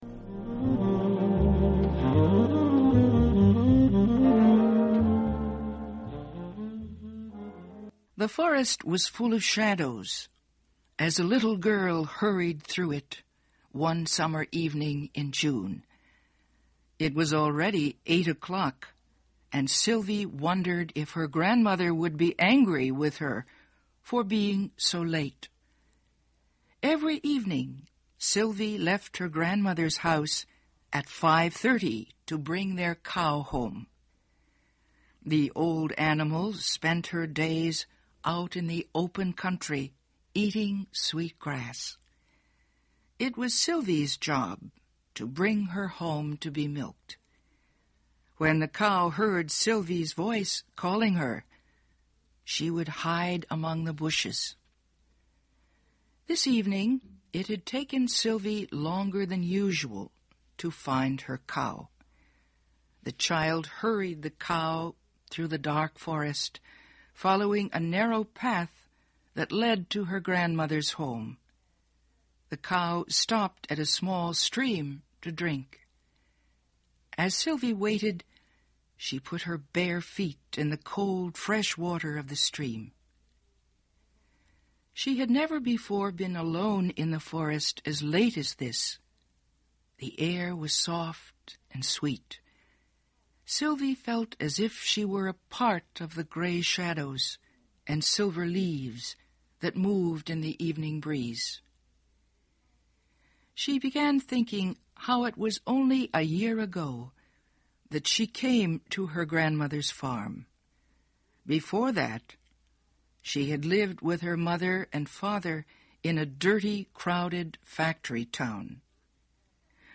We present the short story "A White Heron" by Sarah Orne Jewett.